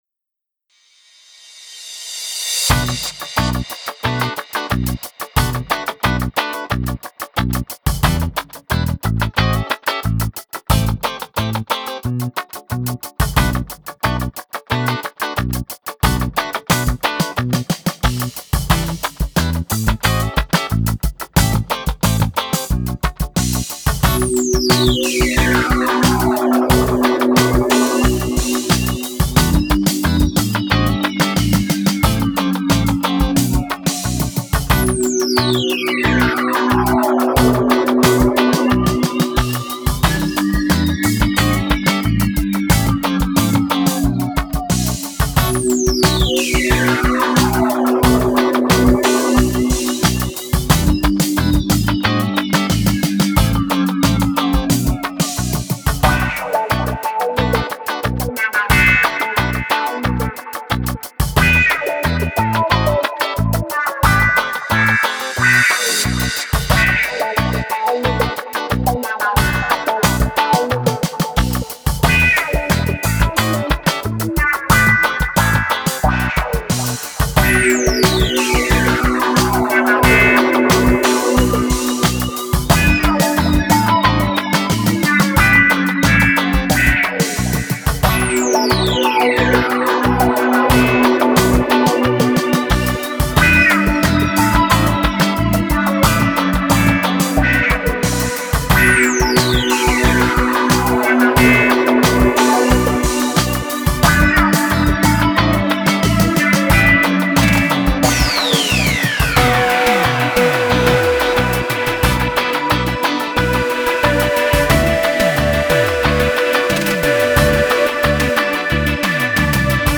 Genre: Ambient,Electronic.